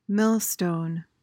PRONUNCIATION: (MIL-stohn) MEANING: noun: 1.